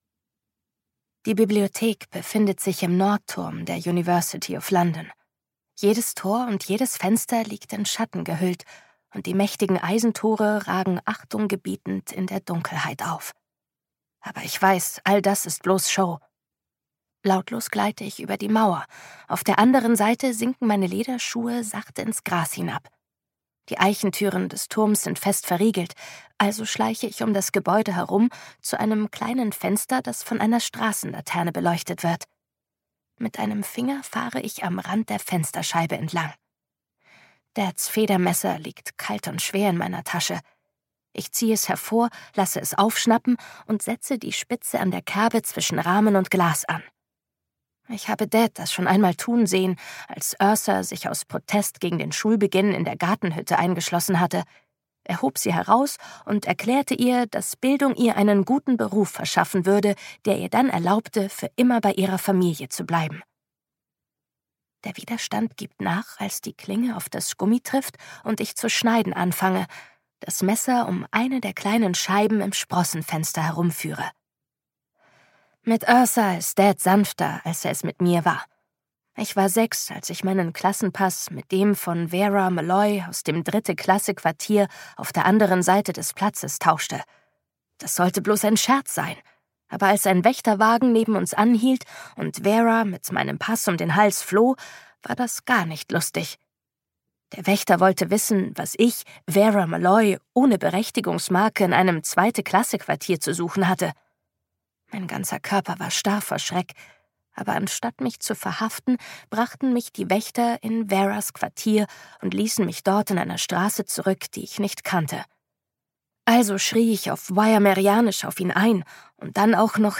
Die Sprache der Drachen (DE) audiokniha
Ukázka z knihy